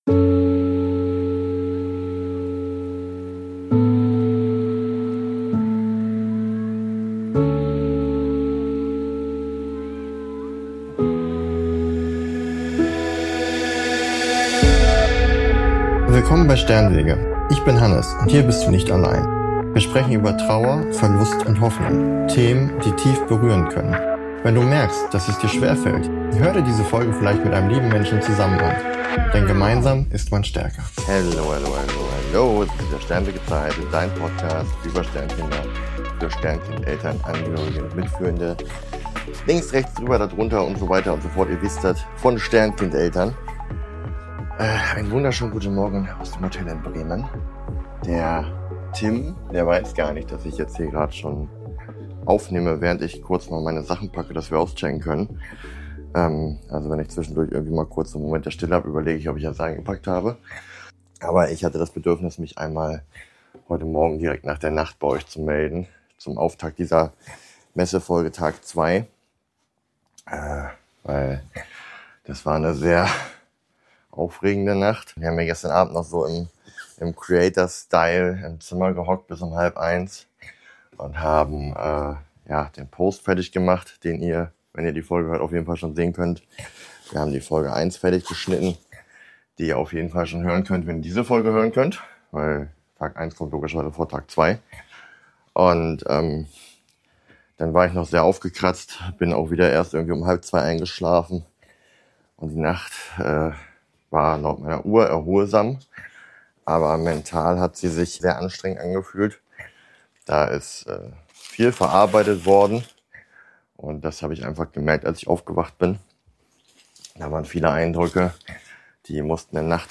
Diese Folge ist - wie schon Tag 1 - kein fertiger Rückblick mit Abstand, sondern ein ungefilterter Live-Eindruck. Direkt aus dem Moment heraus.